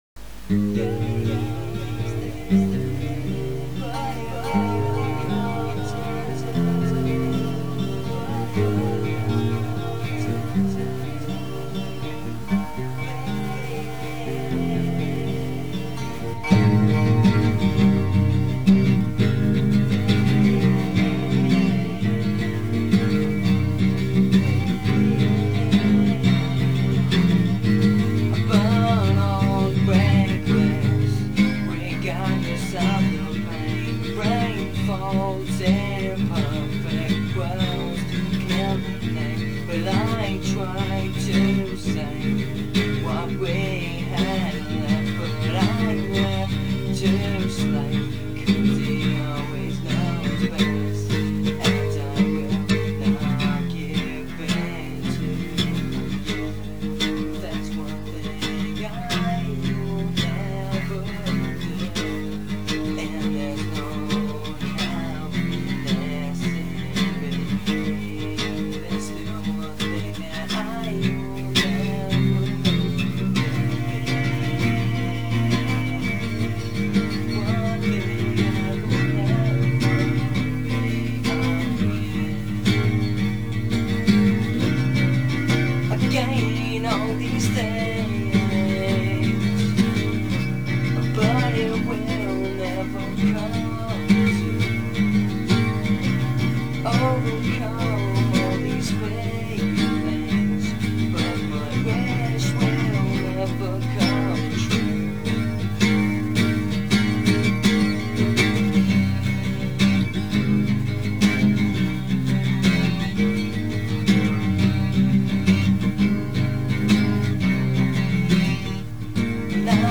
Acoustic Song